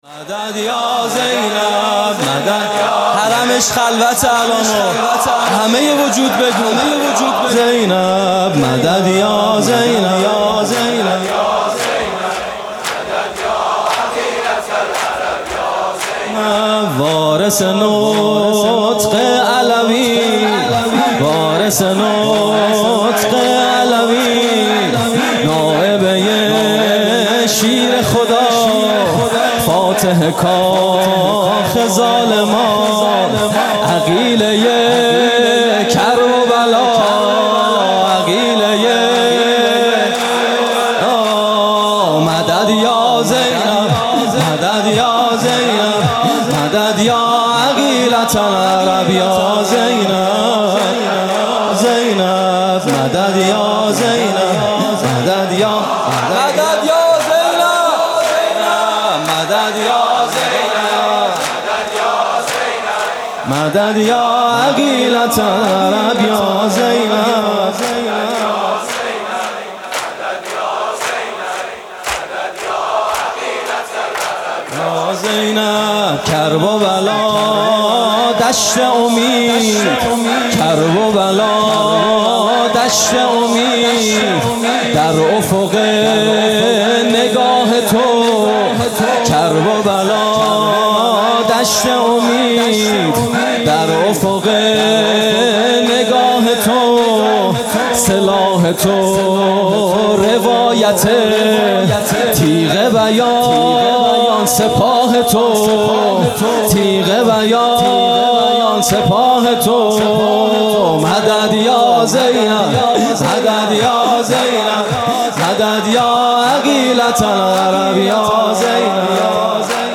music-icon شور: گریه اگر کنم فقط بهر تو گریه می کنم